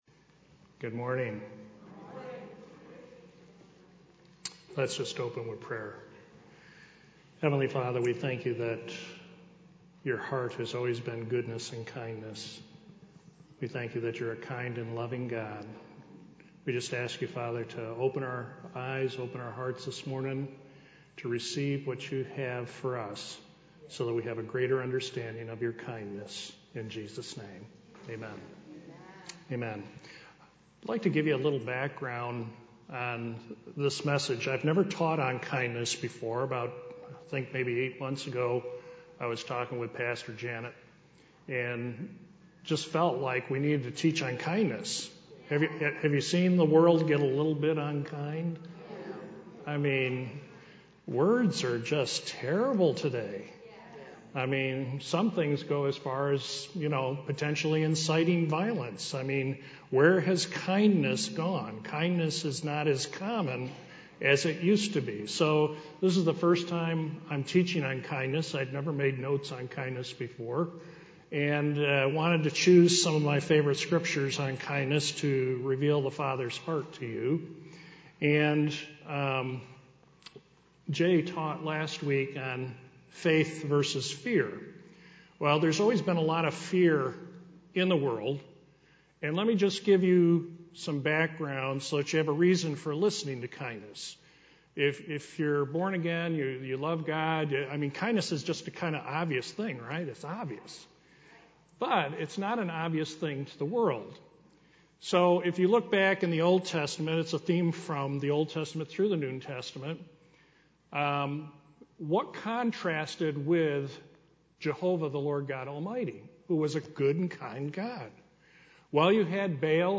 Sermons Archive - Page 31 of 47 - River of Life Community Church